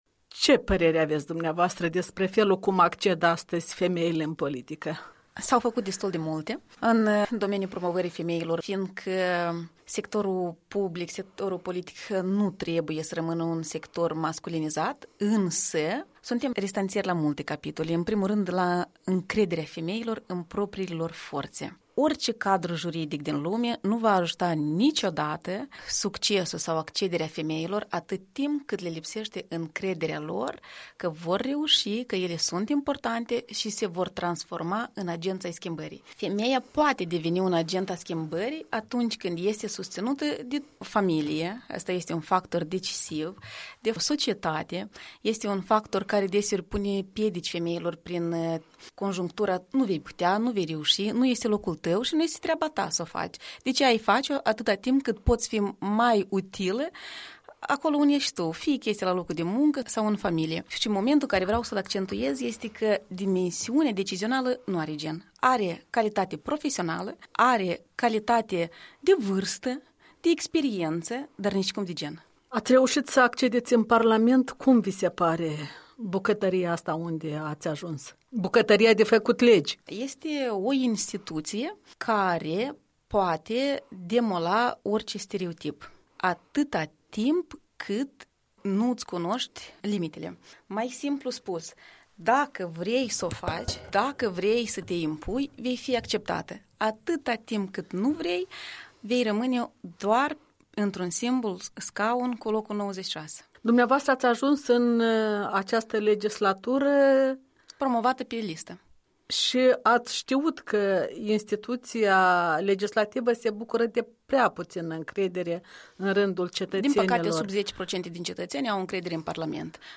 Despre promovarea femeilor în politică în dialog cu o deputată a PAS.